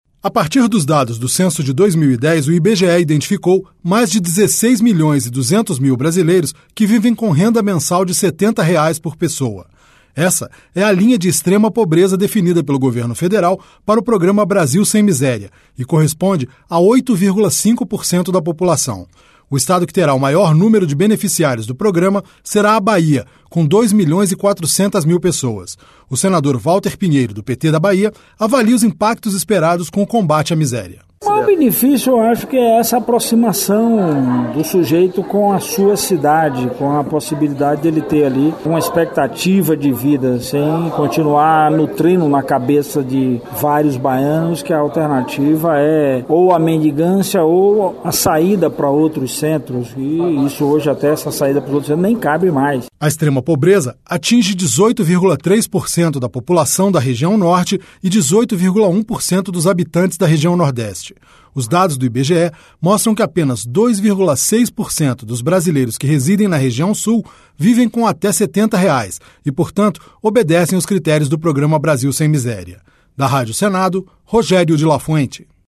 O senador Walter Pinheiro, do PT da Bahia, avalia os impactos esperados com o combate à miséria.